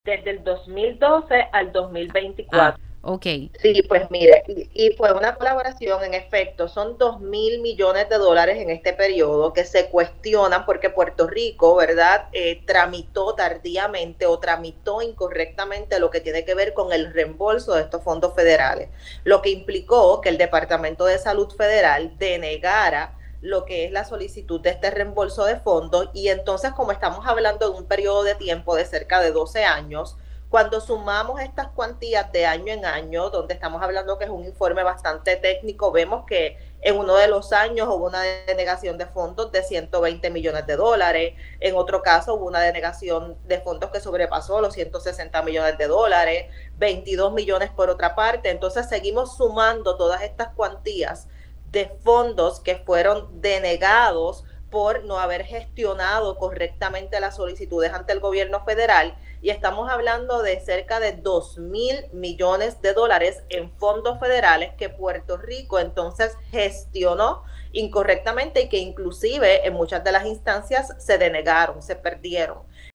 Son 2 mil millones de dólares en este periodo que se cuestionan porque Puerto Rico tramitó tardíamente o tramitó incorrectamente lo que tiene que ver con el reembolso de estos fondos federales, lo que implicó que el Departamento de Salud Federal denegara lo que es la solicitud de este reembolso de fondos”, explicó la Inspectora General, Ivelisse Torres en Pega’os en la Mañana.